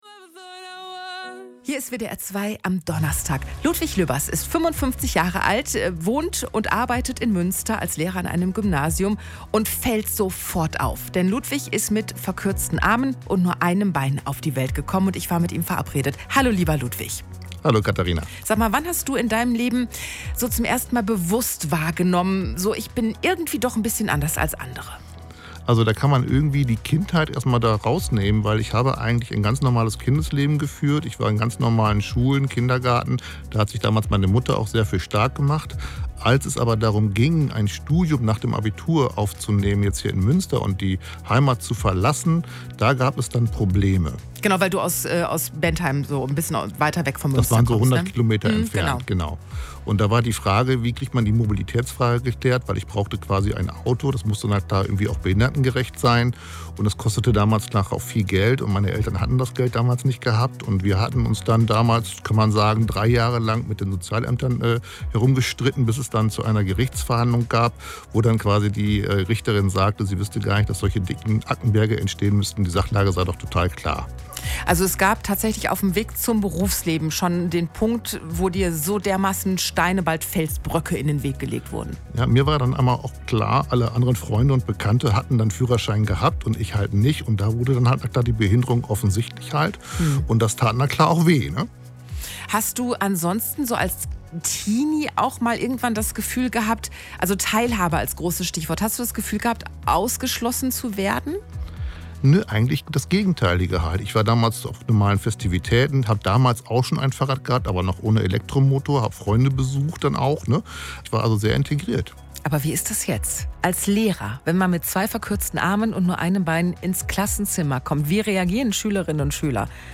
Dann hören Sie selbst in das Interview hinein und erfahren Sie mehr über die Geschichte unseres Kollegen!